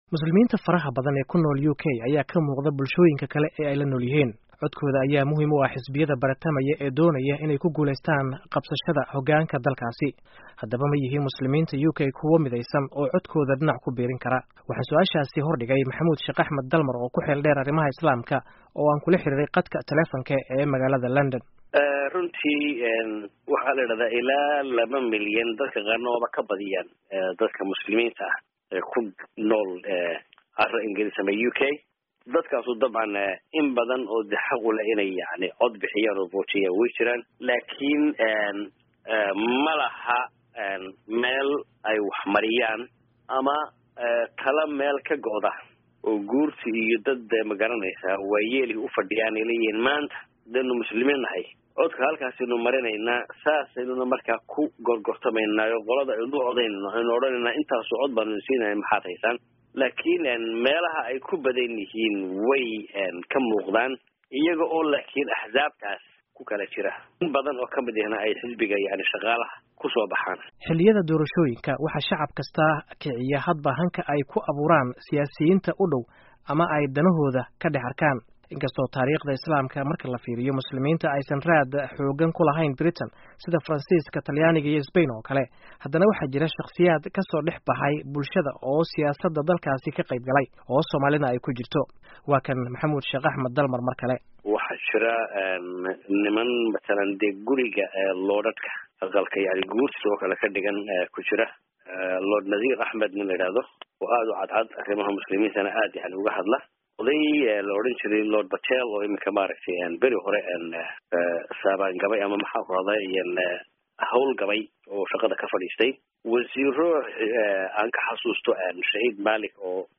Dhageyso Warbixinta Doorashooyinka UK